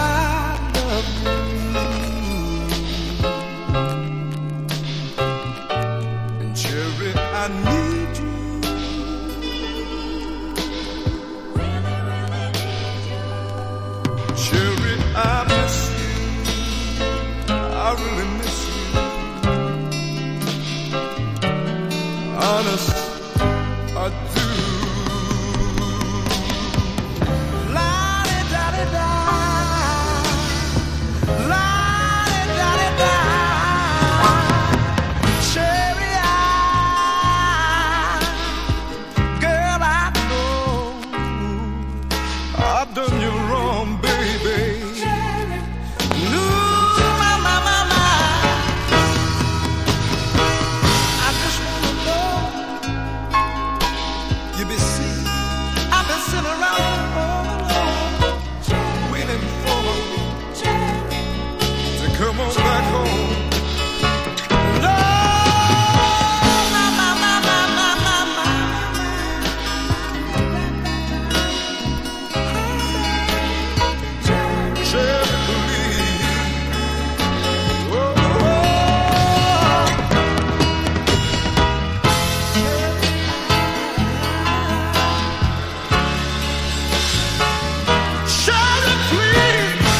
込み上げ系のホーンセクションと女性コーラスがアガるエレクトロ・ファンクディスコ名曲。